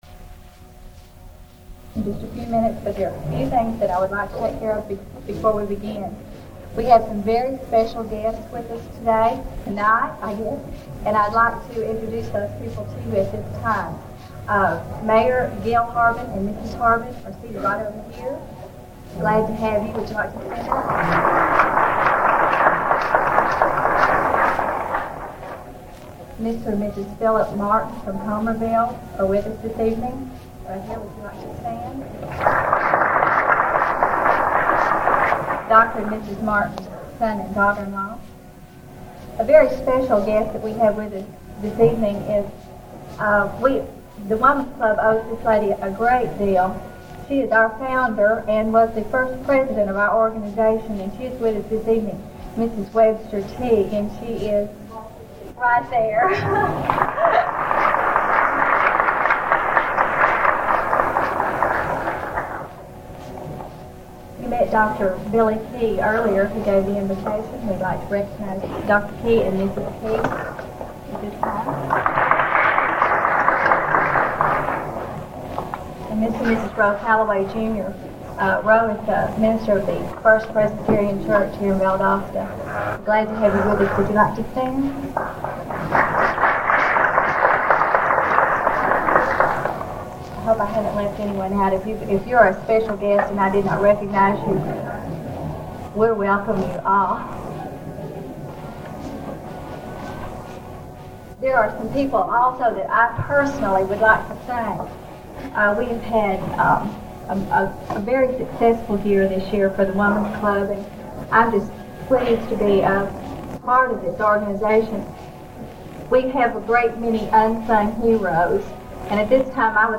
Audio recording of the Valdosta State College Founders Day Program, 1978. Digitized from original audio cassette tape at Valdosta State University Archives and Special Collections.